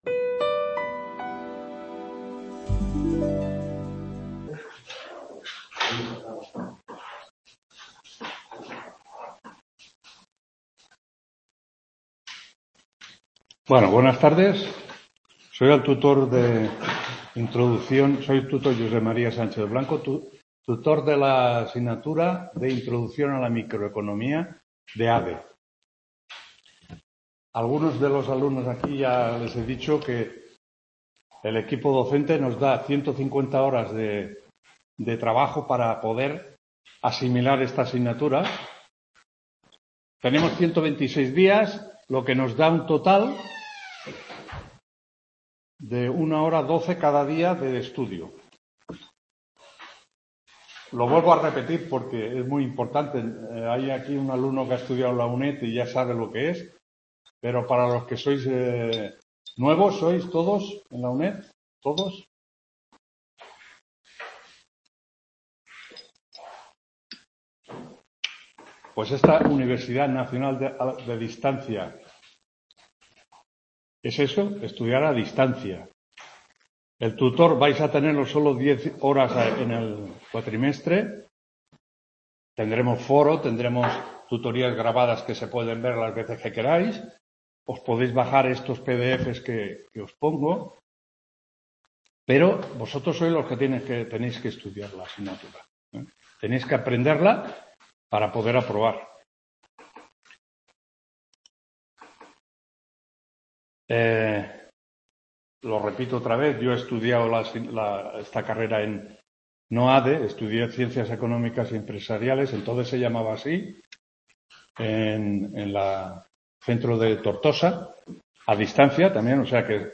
1ª TUTORÍA INTRODUCCIÓN A LA MICROECONOMÍA 08-10-24 … | Repositorio Digital